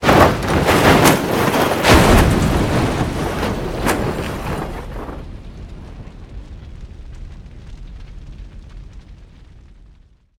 vehicleexplode.ogg